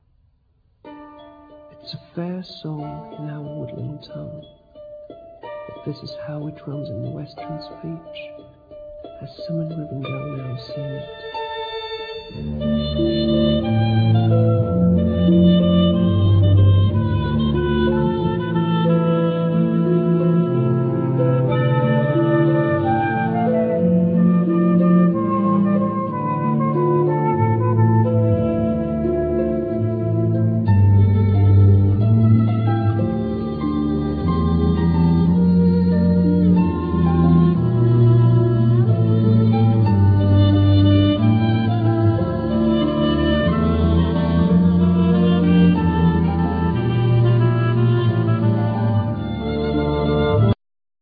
Flute
English horn
Clarinet,Vocals
Basson
Violin
Cello
Harp